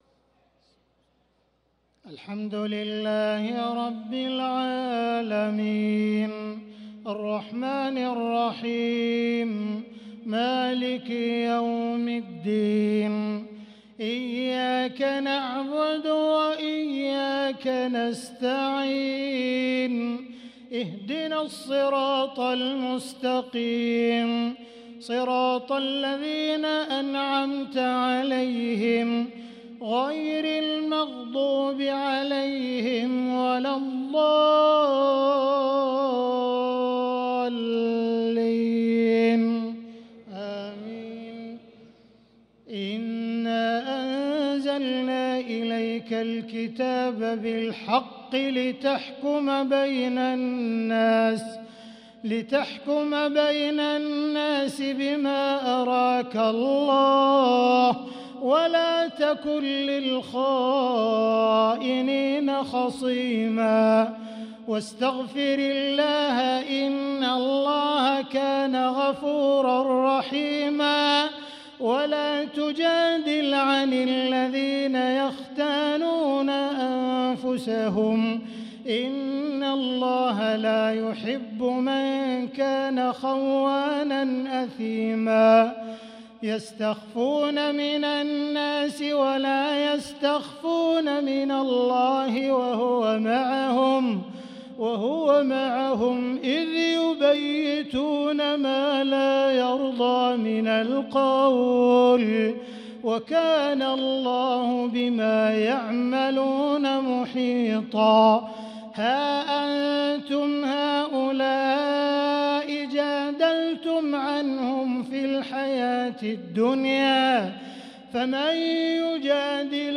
صلاة العشاء للقارئ عبدالرحمن السديس 9 شعبان 1445 هـ
تِلَاوَات الْحَرَمَيْن .